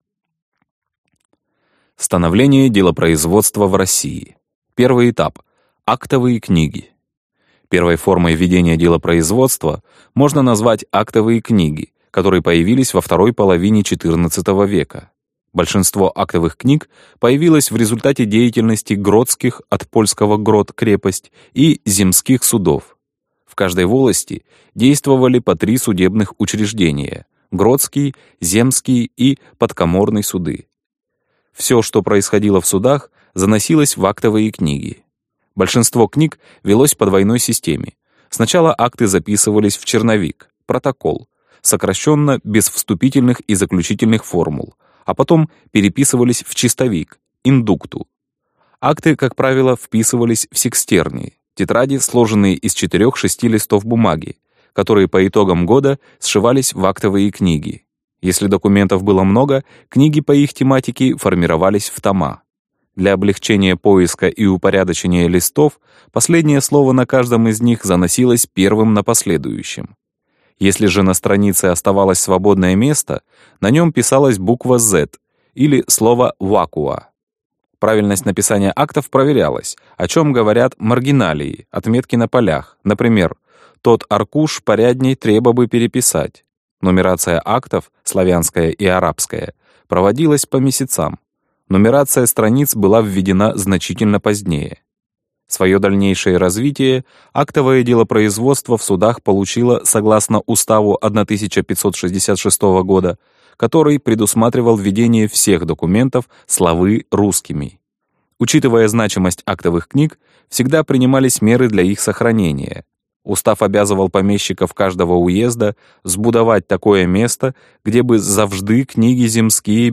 Аудиокнига Лекции по делопроизводству | Библиотека аудиокниг